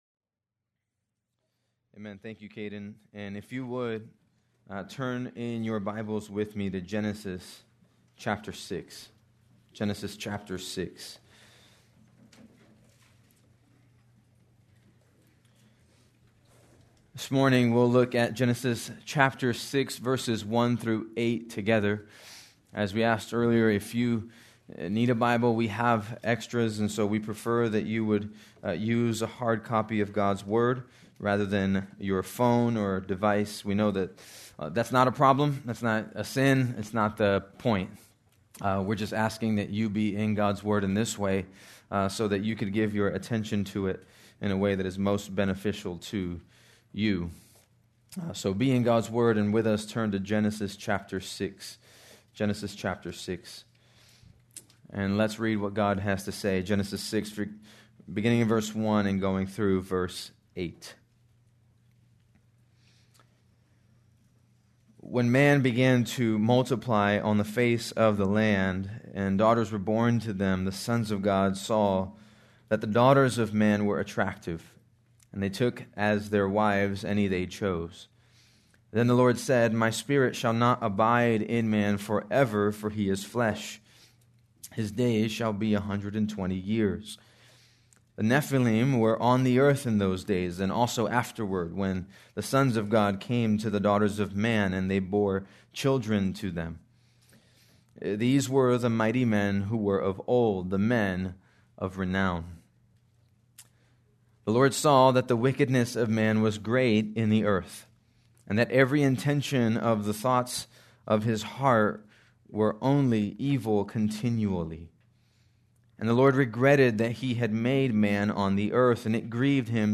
April 19, 2026 - Sermon